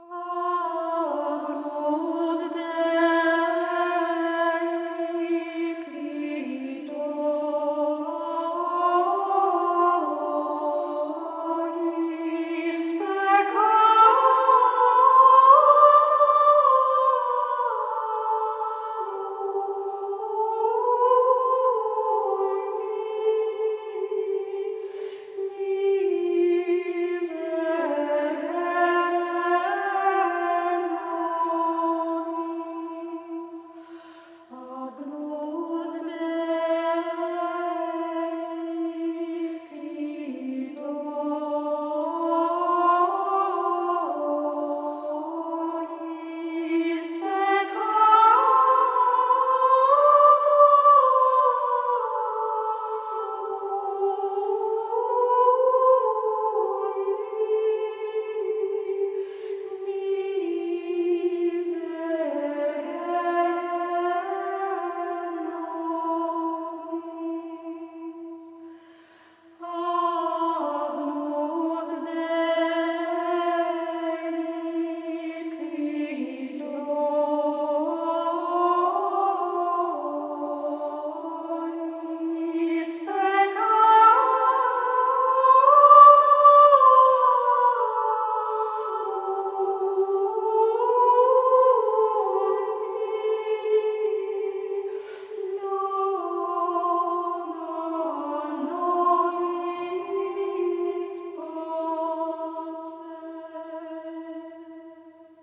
Il est emprunté au 4ème mode et sa mélodie, assez ornée et développée et expressive, le rend tout à fait apte à s’insérer dans des messes solennelles. Mais comme tous les Agnus Dei grégoriens, il se caractérise surtout par un grand calme, une grande confiance, une immense douceur.
Les trois invocations ont la même mélodie, à un détail près : le second Agnus, sur son accent, ne possède pas le climacus Fa-Mi-Ré des première et troisième invocations, mais juste un punctum situé sur le Do grave.
La mélodie de Agnus Dei s’enroule d’emblée autour du Mi, tonique du 4ème mode.
Cette courbe très belle, très expressive doit être marquée évidemment par un crescendo significatif.
Au total, un Agnus Dei orné, très chaud, très plein, très serein dans son expression qui ne manque pas de force et de supplication.